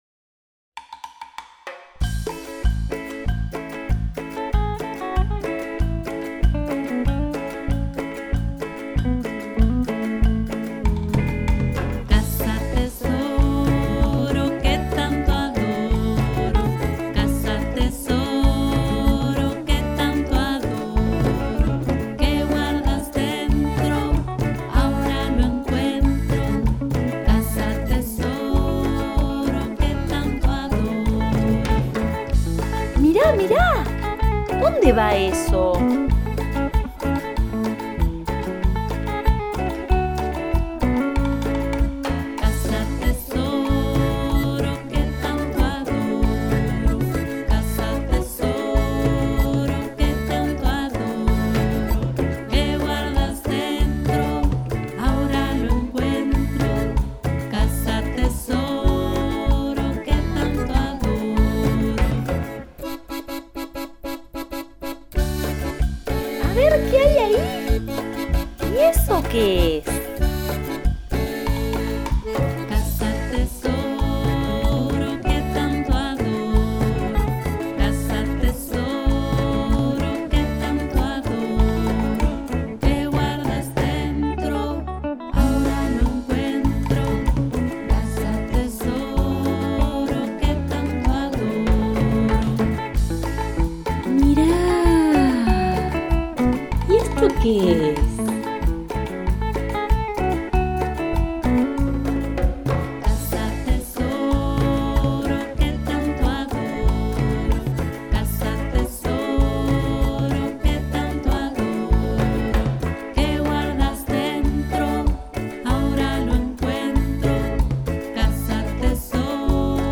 Acordeón
Guitarras y bajo
Percusión